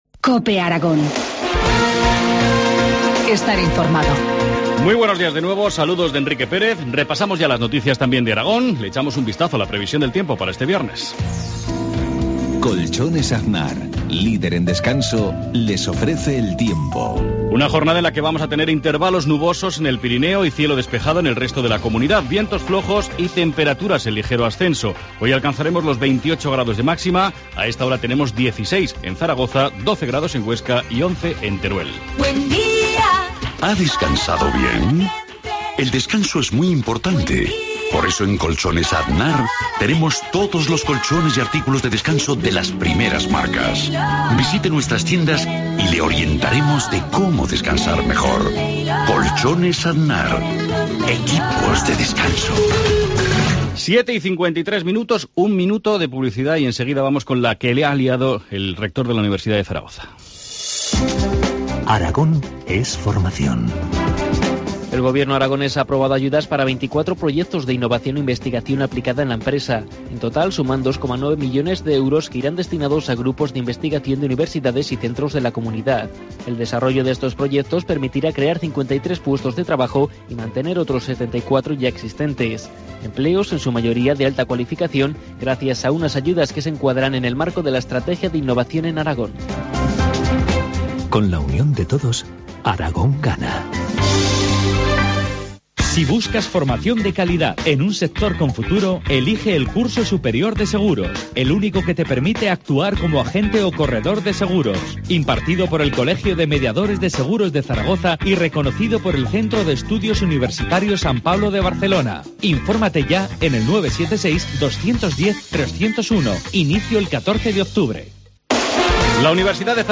Informativo matinal, viernes 20 de septiembre, 7.53 horas